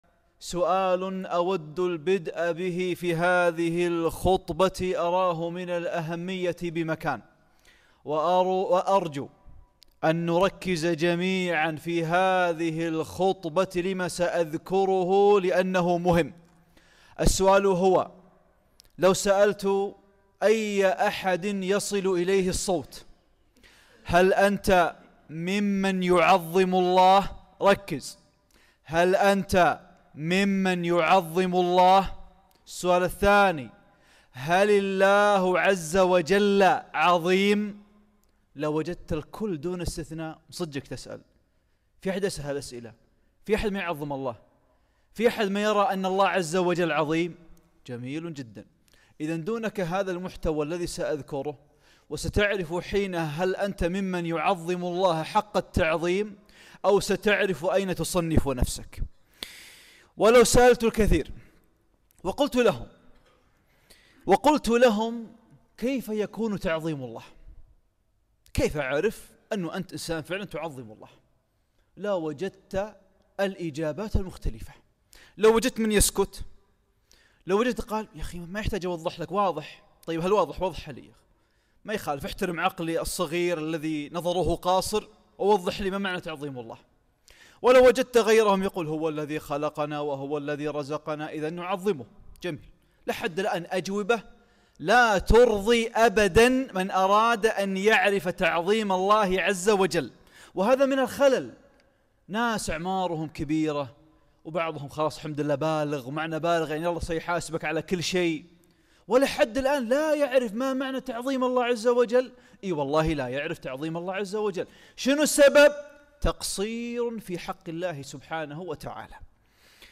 خطبة - تعظيم الله عز وجل